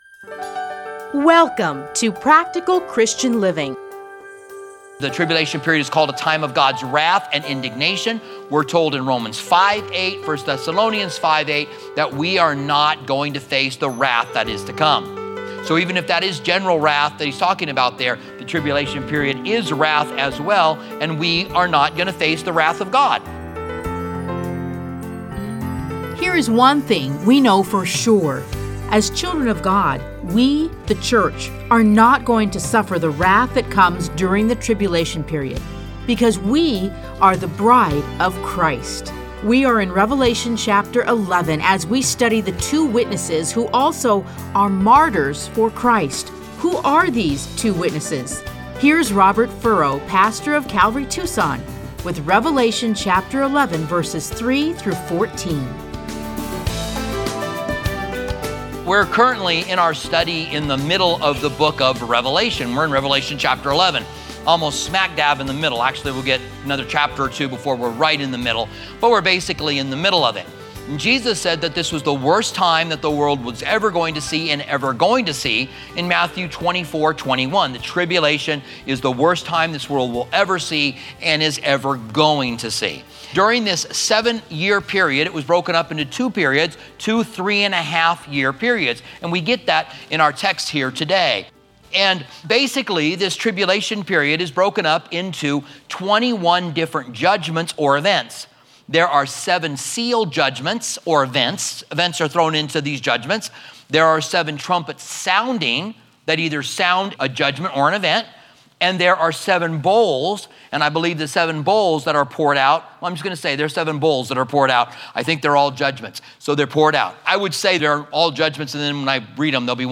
Listen to a teaching from Revelation 11:3-14.